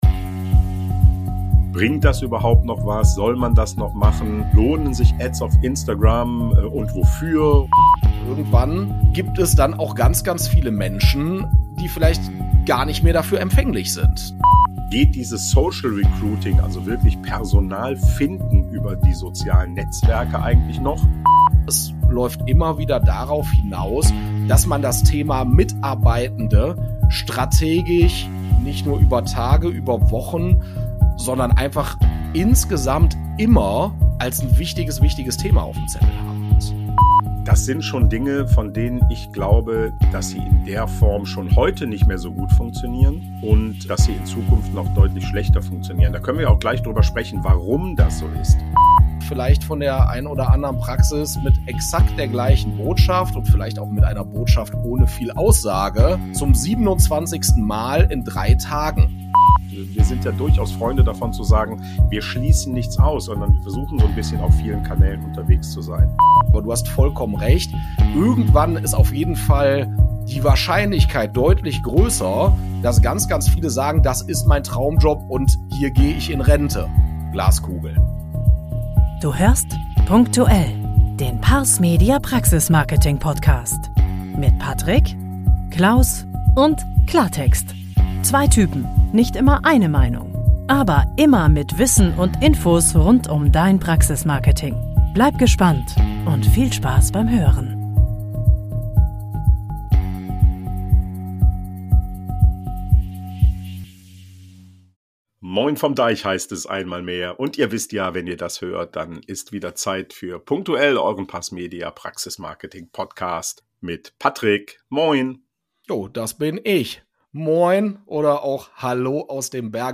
Sie sprechen über die Veränderungen im Arbeitsmarkt und wie sich Praxen darauf einstellen können. Im zweiten Teil des Gesprächs geht es um die Bedeutung von Employer Branding im Social Recruiting, sowie um verschiedene Themen, die auf die Arbeitgebermarke einzahlen können.